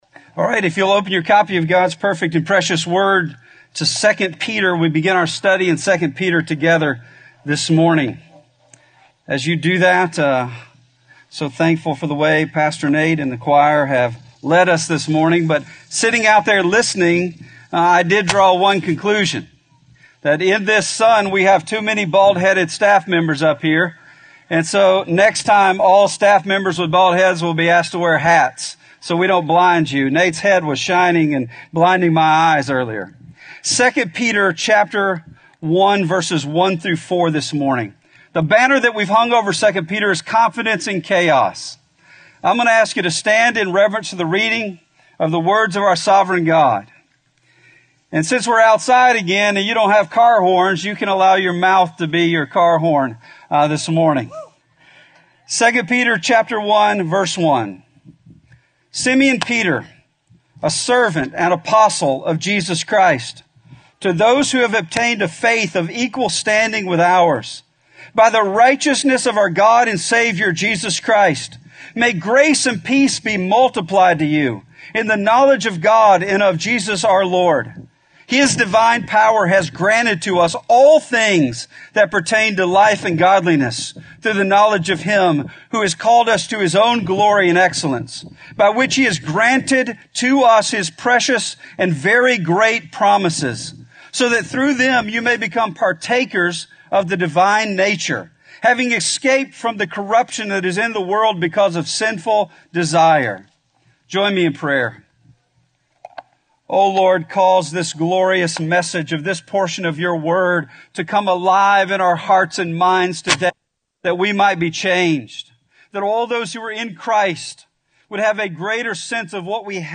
We begin our 2 Peter series, "Confident in Chaos," with a sermon entitled "Granted All Things." We have everything we need to have confidence in this world.